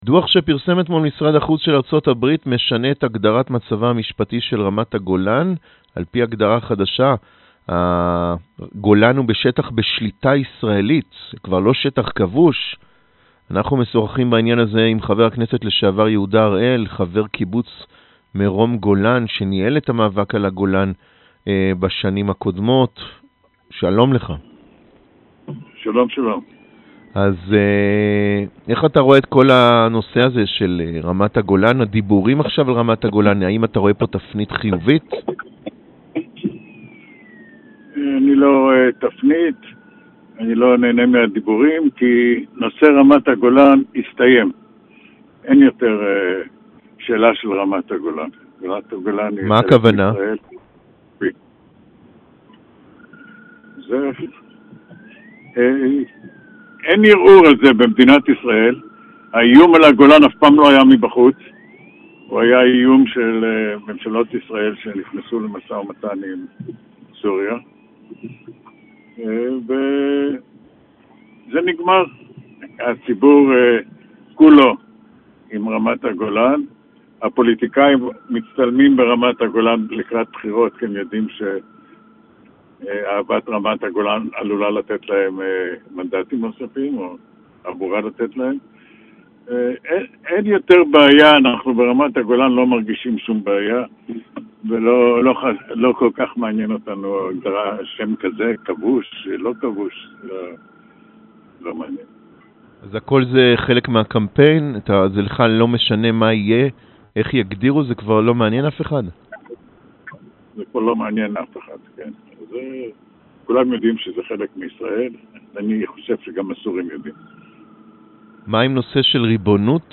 Полностью интервью с Иегудой Харелем можно прослушать, перейдя по этой ссылке .